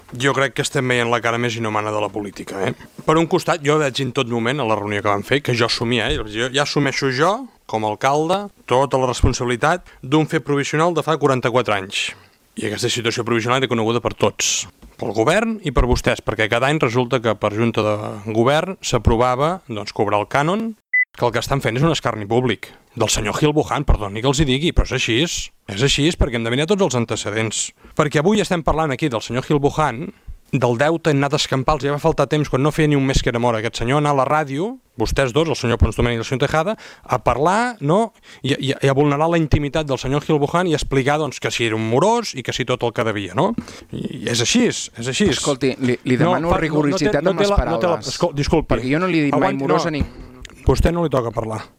Debat tens en l’última sessió plenària per les posicions enfrontades entre govern i oposició sobre la gestió del bar El Paso, que posarà punt final a la seva activitat amb el canvi d’any.